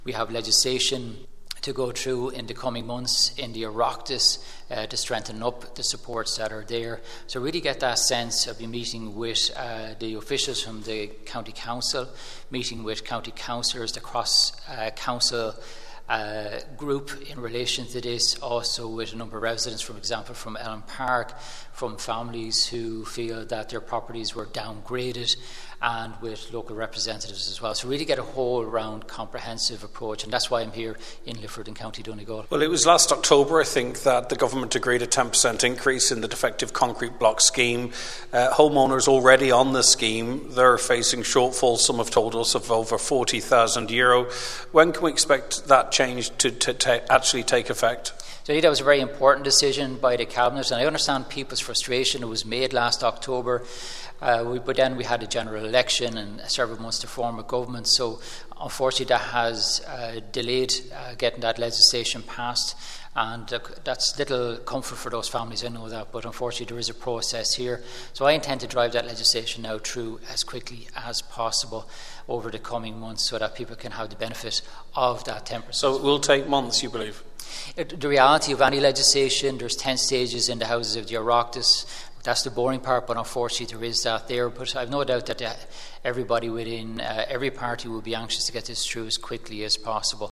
in Lifford this afternoon before a meeting with Donegal County Council’s Defective Blocks Committee